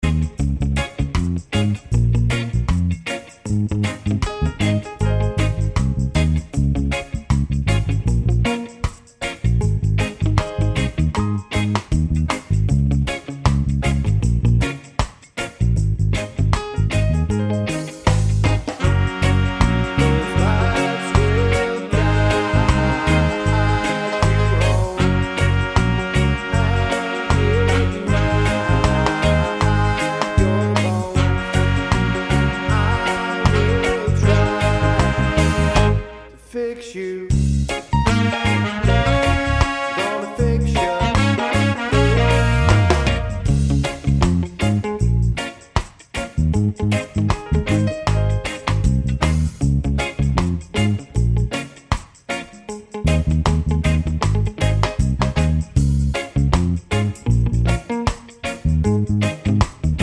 Cut Down Intro and No Backing Vocals Ska 3:47 Buy £1.50